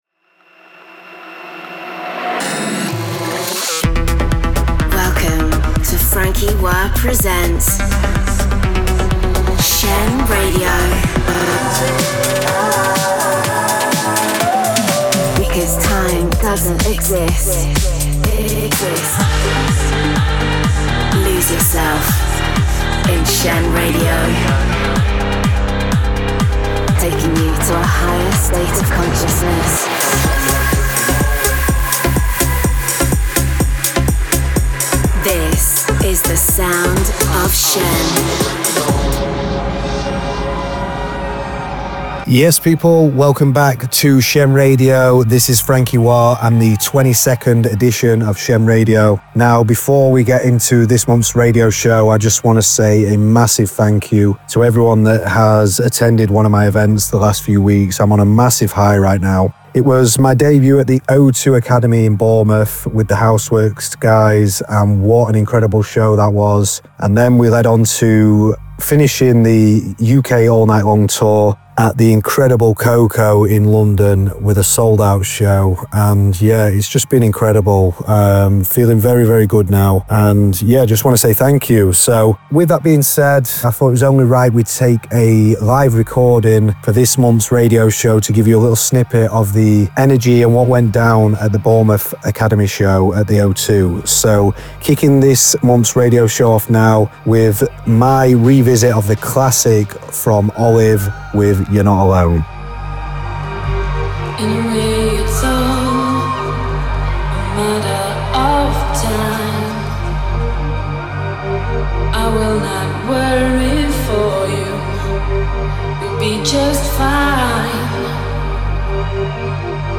conceptual, cutting-edge, progressive sounds